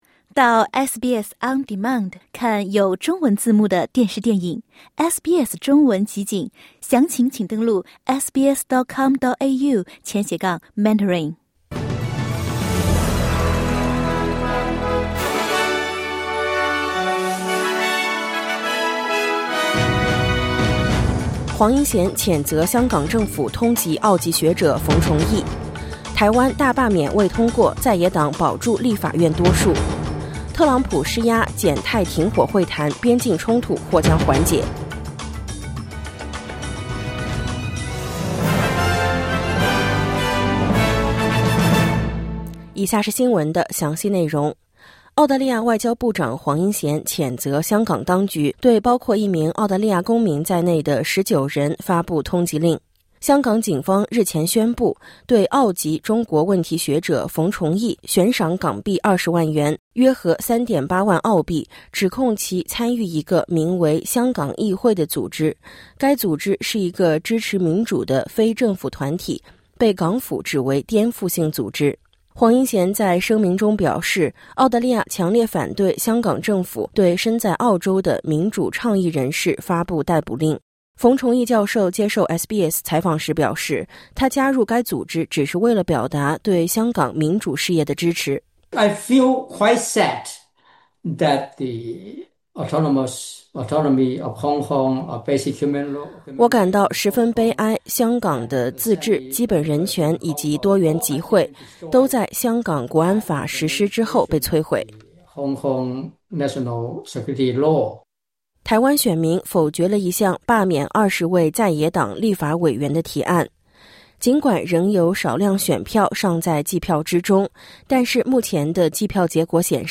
SBS早新闻（2025年7月27日）